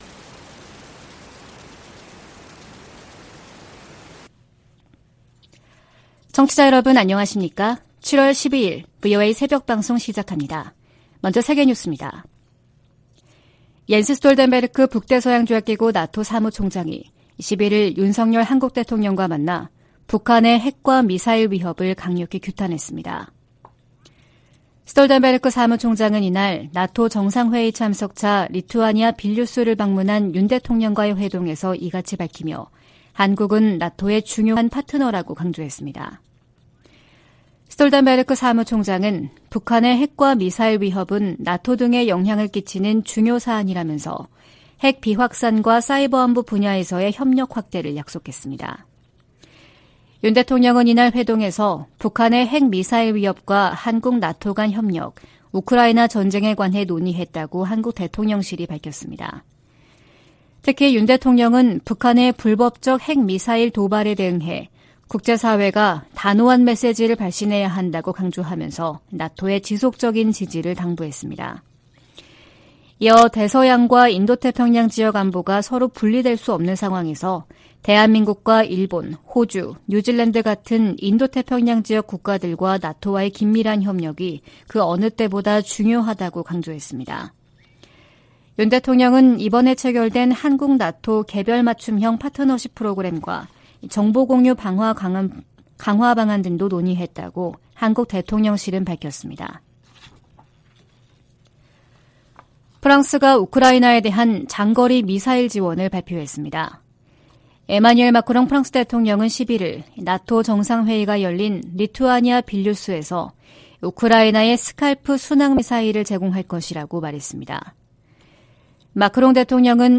VOA 한국어 '출발 뉴스 쇼', 2023년 7월 12일 방송입니다. 북한 김여정 노동당 부부장이 이틀 연속 미군 정찰기의 자국 상공 침범을 주장하며 군사적 대응을 시사하는 담화를 발표했습니다. 이와 관련해 미 국무부는 북한에 긴장 고조 행동 자제를 촉구했습니다. 미한 핵 협의그룹 NCG 첫 회의가 다음 주 서울에서 열립니다.